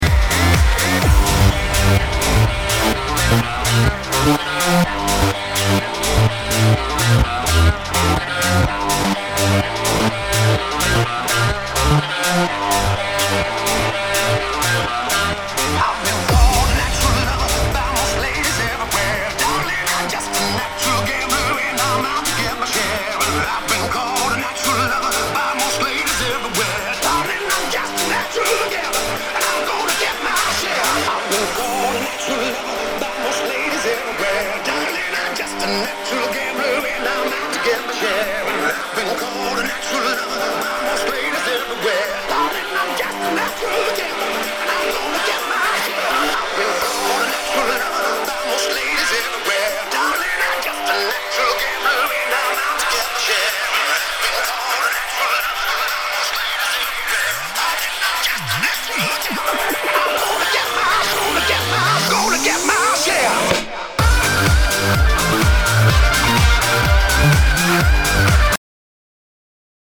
House / Tribal House